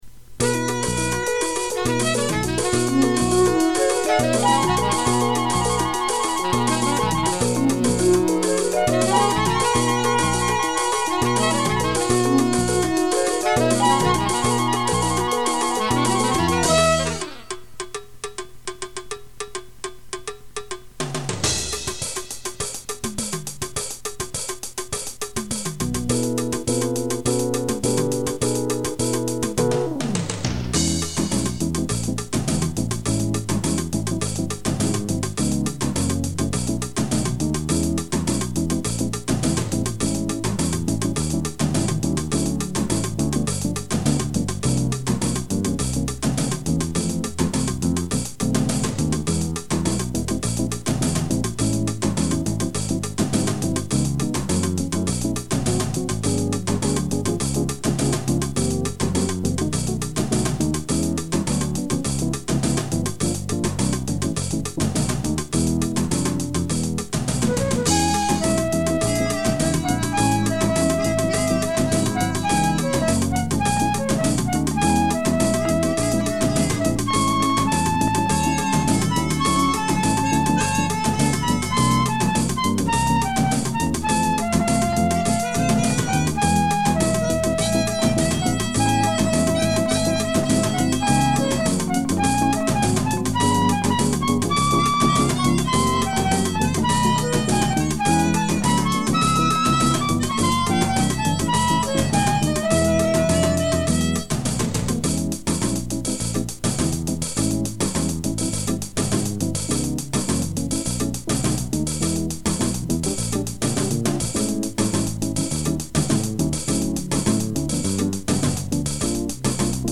MORE TO PLAY-ALONG WITH